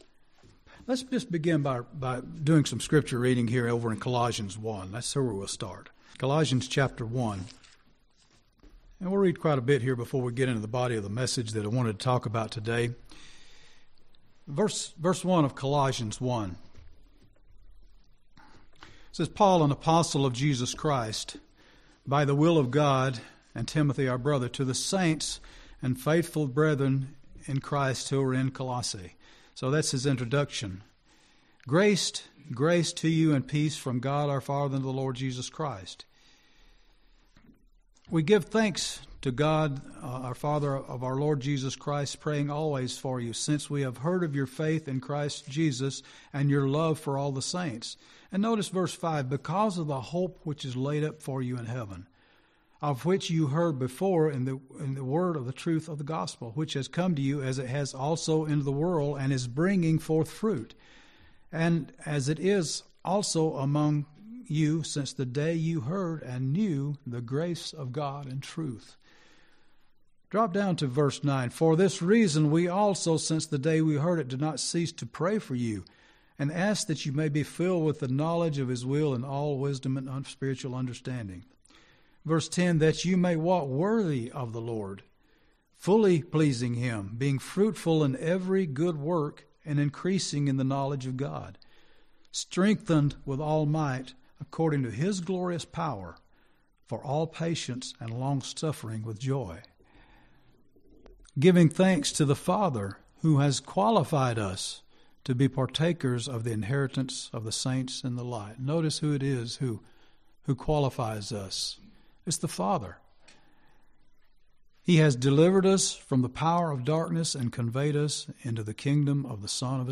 Sermons
Given in Gadsden, AL